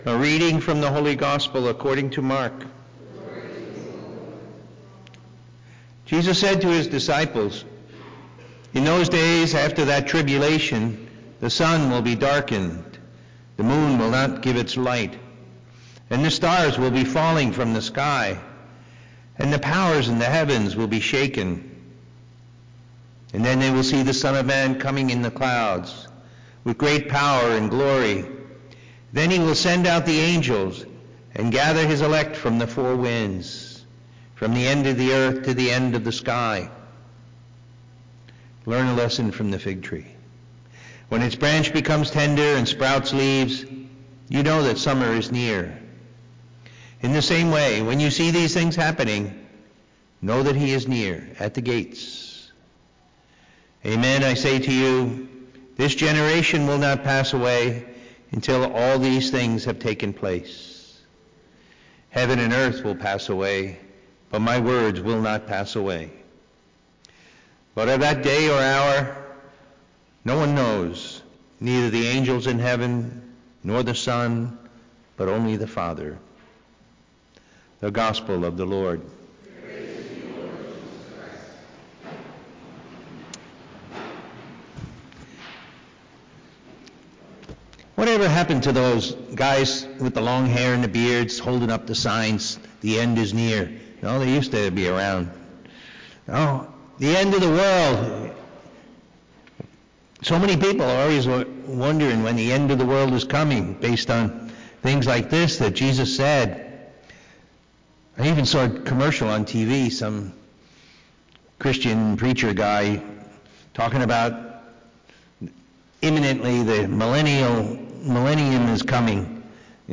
Listen to the homily from the Sunday Mass and meditate on the Word of God.